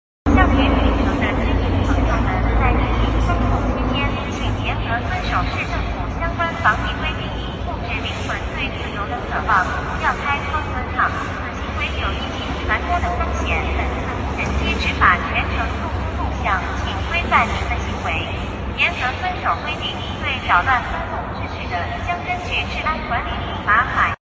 Hier Aufnahmen aus dem Lockdown in der Stadt Chengdu, China. Eine Drohne erscheint: Steuere den Wunsch deiner Seele nach Freiheit!